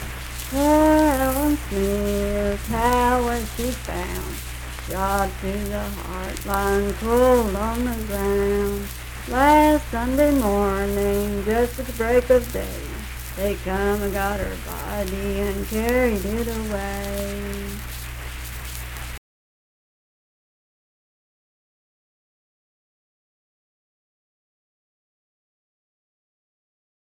Unaccompanied vocal music
Verse-refrain 2(4).
Voice (sung)
Braxton County (W. Va.), Sutton (W. Va.)